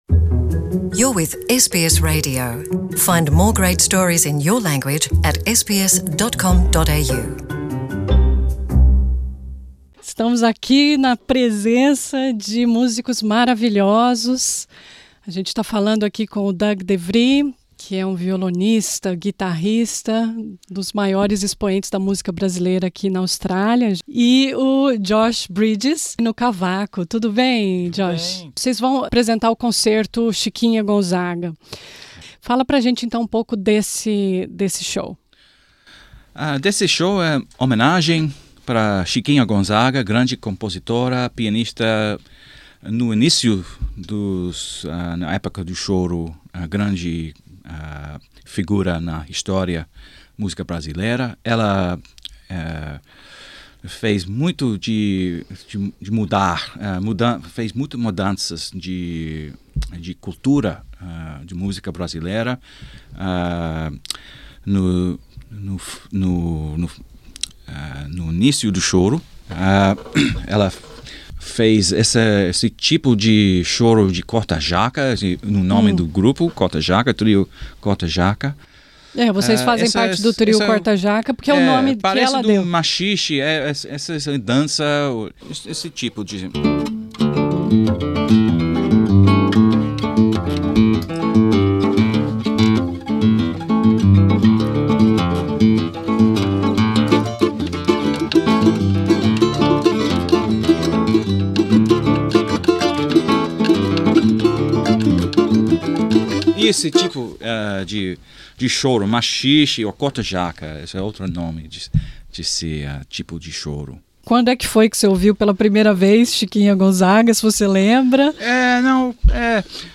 no violão sete cordas
no cavaco
Nessa entrevista